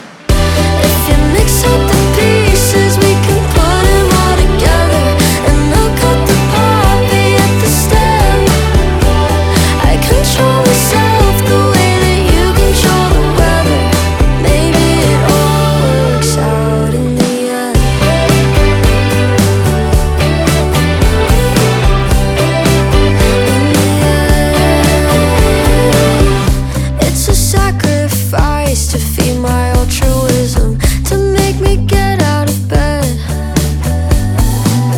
Indie Pop Alternative
Жанр: Поп музыка / Альтернатива